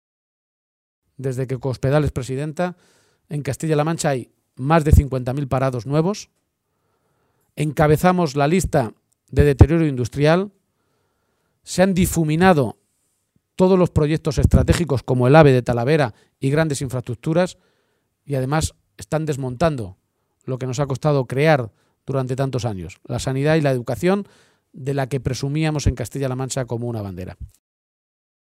La dirección regional del Partido Socialista de Castilla-La Mancha se ha reunido esta tarde en Talavera de la Reina.